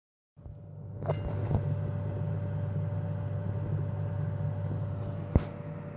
Ad un certo punto, dopo un pò che ci smanettavo senza aver cambiato nulla di rilevante, spegnendo il PC e riaccendendolo, il monitor è rimasto completamente nero e dalla scheda sua scheda veniva fuori questo sibilo intermittente: